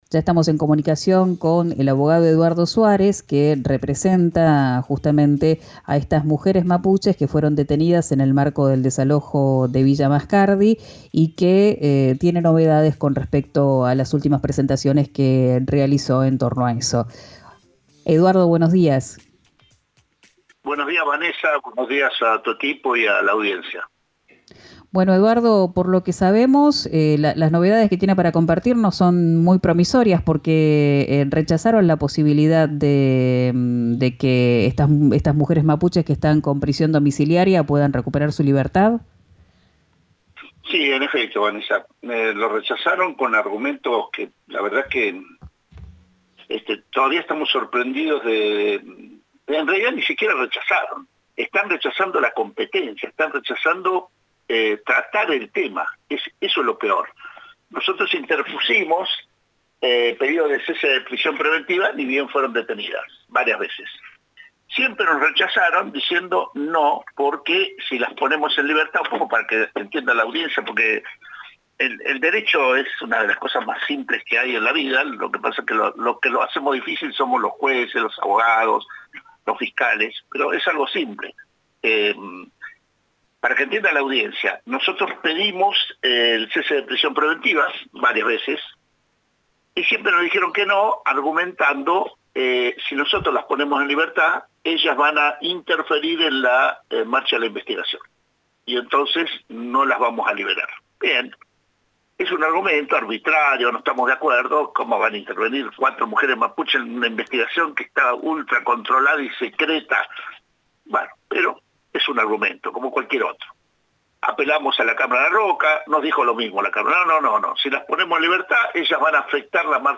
en el programa «Quien dijo Verano» que se emite por RÍO NEGRO RADIO.